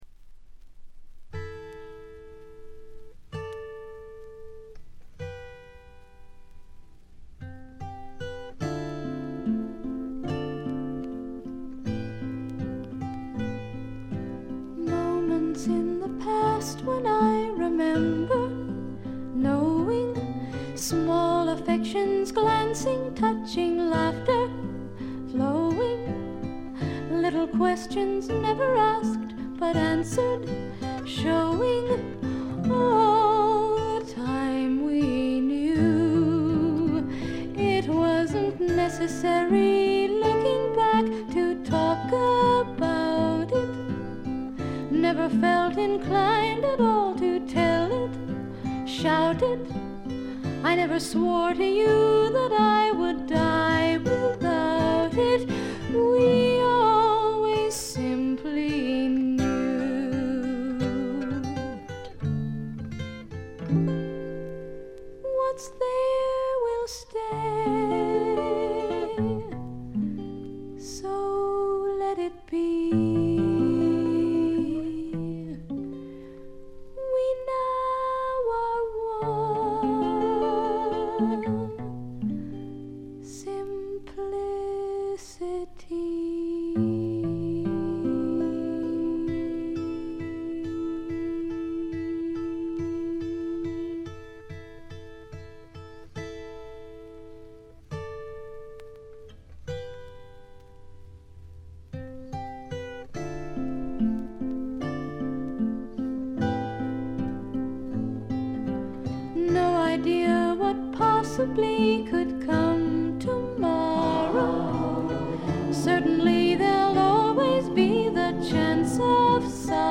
ごくわずかなノイズ感のみ。
で内容はというと英米の良さを併せ持った素晴らしすぎるフォーク／フォークロックです。
試聴曲は現品からの取り込み音源です。